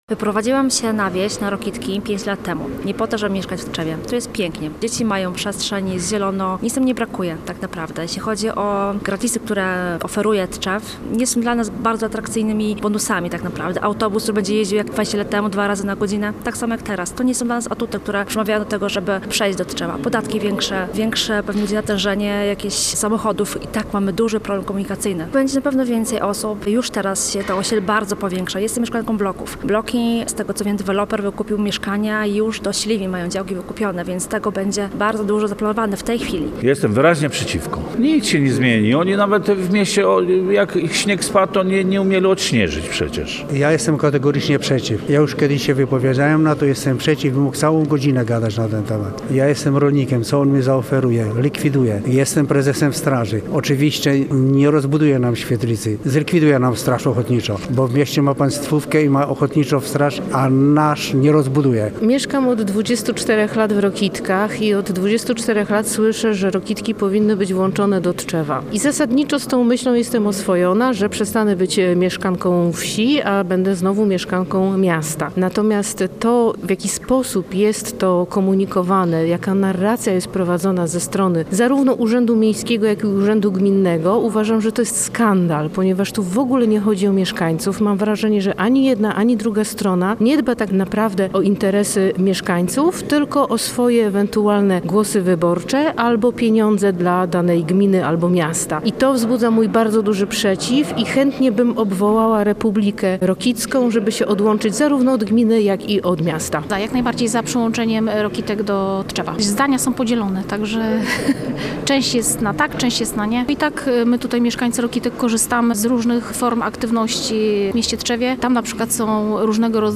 Pytani przez nas mieszkańcy wyrazili zaniepokojenie.